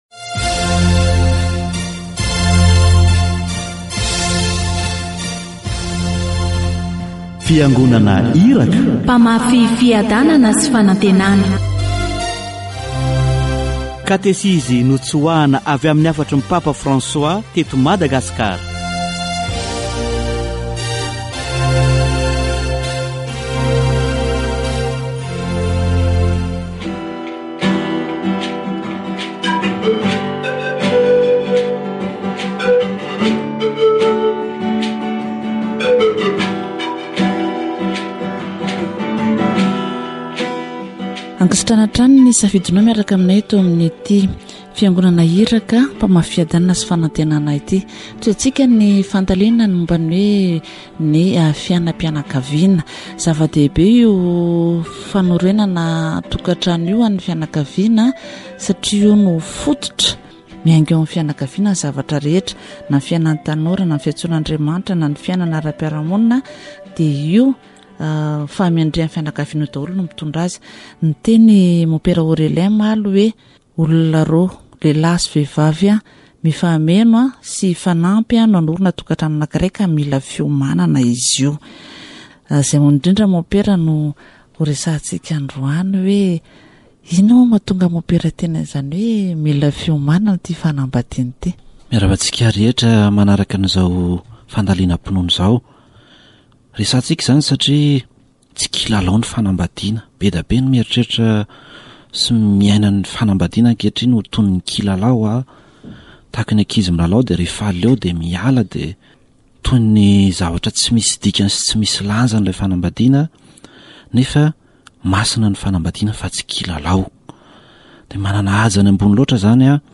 Catéchèse sur le mariage